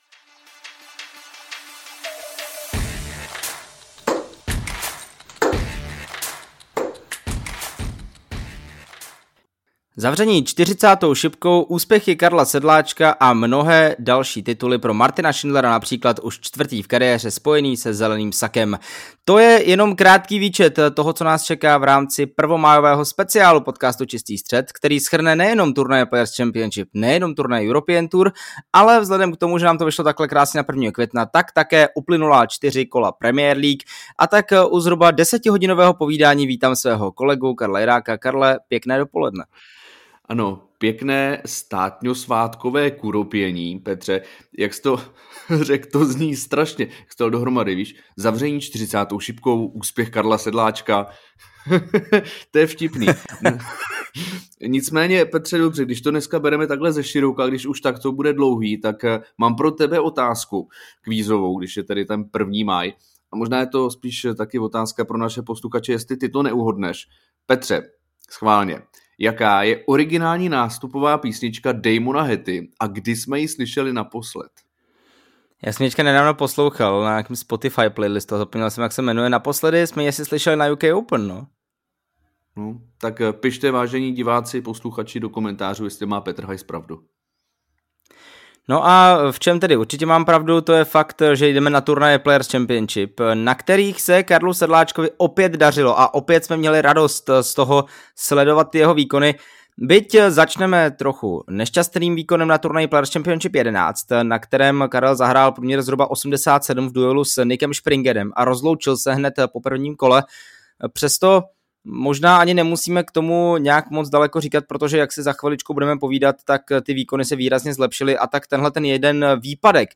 Preview k MS - živý podcast (výběr)